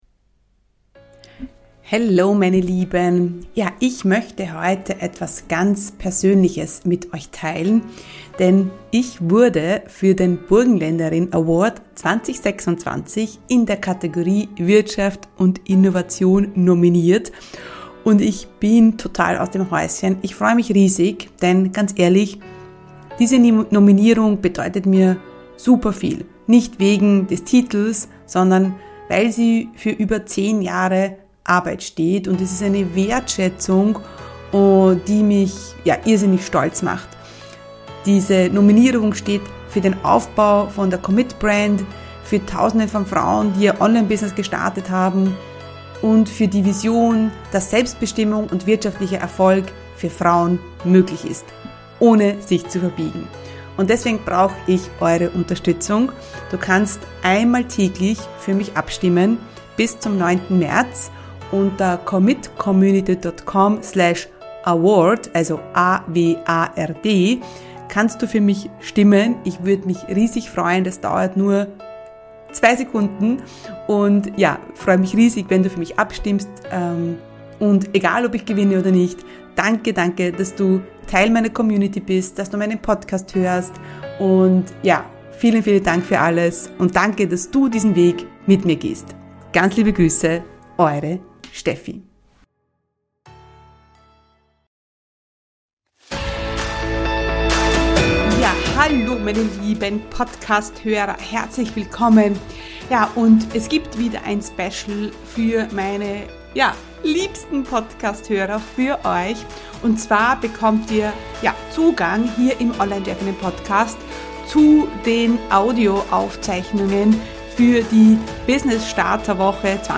342 Online Business Workshop Woche: Positionierung ( Aufzeichnung Tag 1) ~ Online Chefinnen Podcast